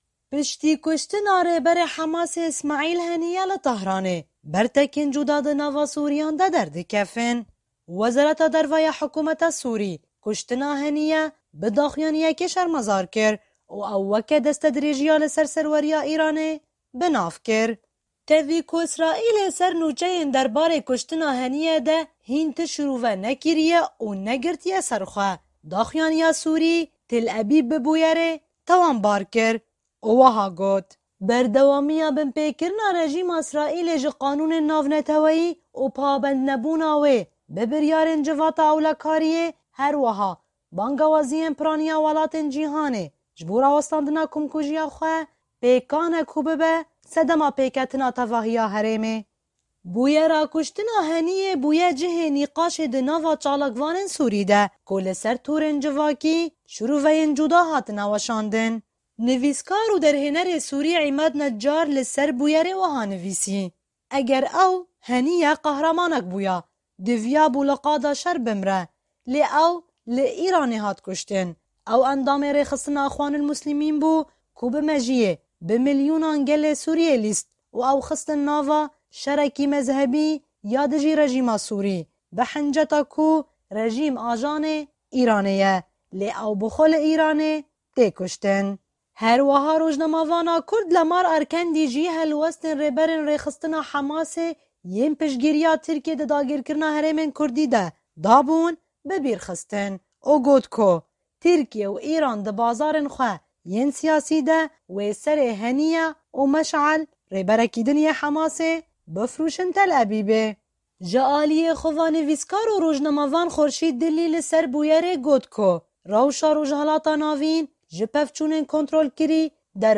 Raporta Efrînê - Kuştina Rêberê Hamasê Îsmaîl Hanîye